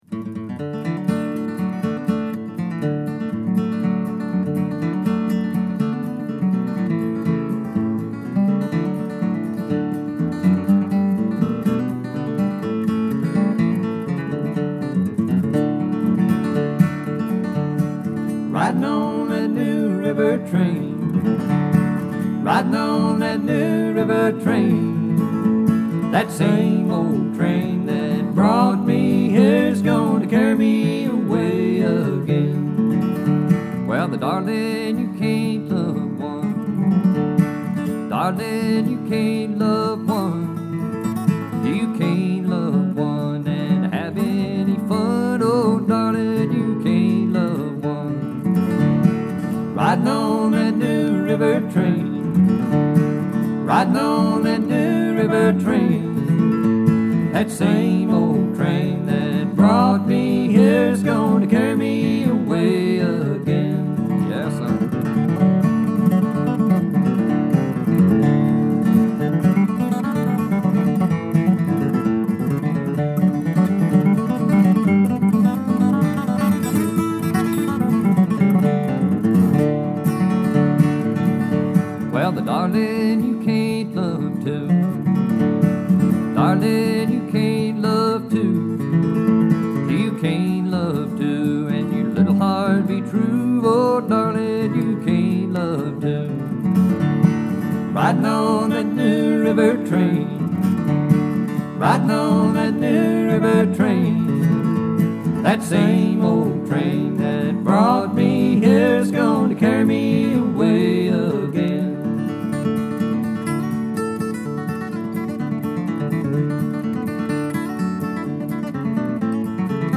Bluegrass gets me through the winter.